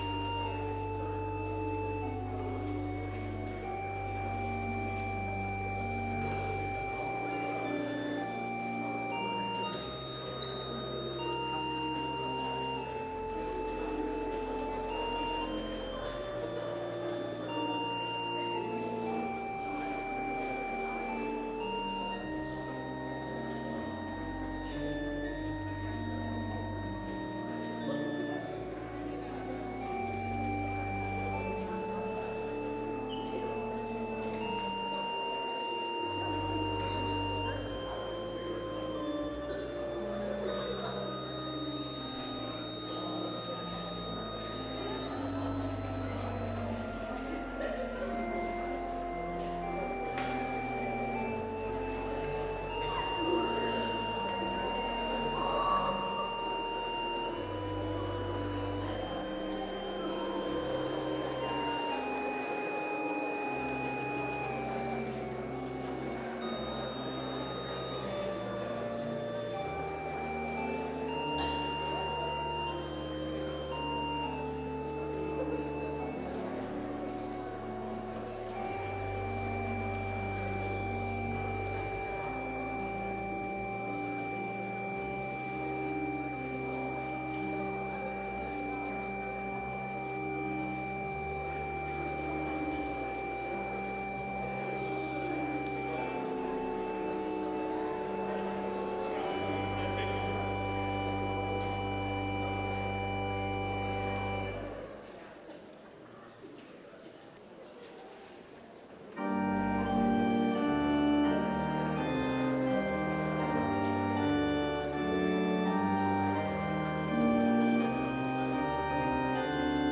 Complete service audio for Chapel - Tuesday, November 4, 2025